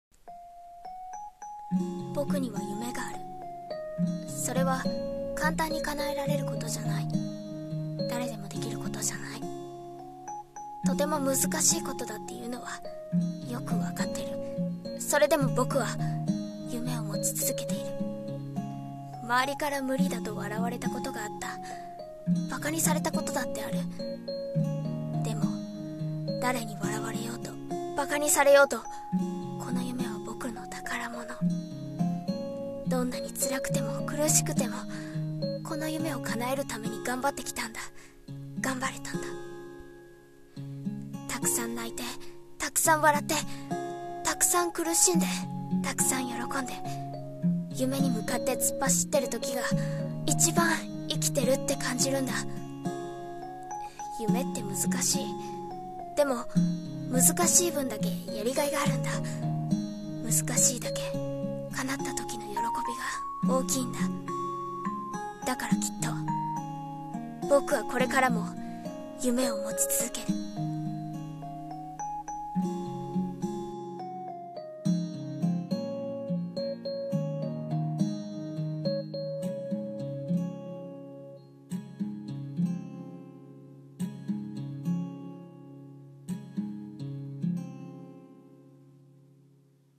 [声劇･朗読]夢を持つ